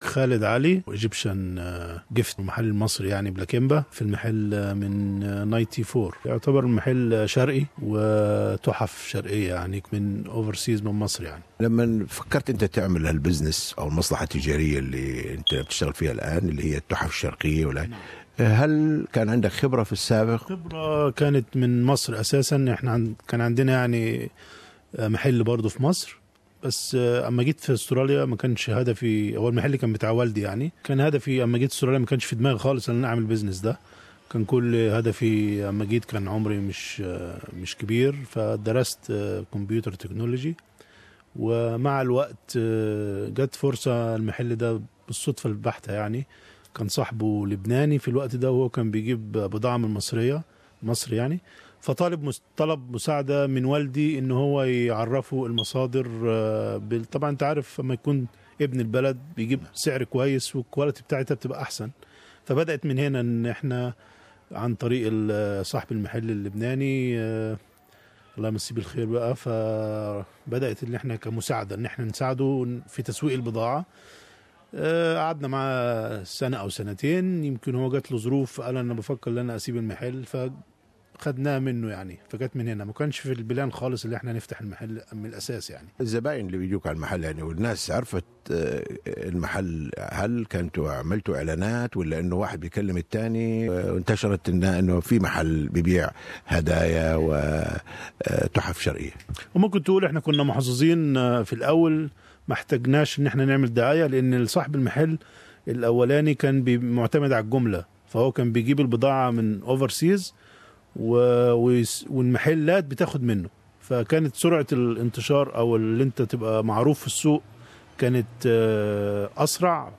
This business represent almost everything Egyptian. Interview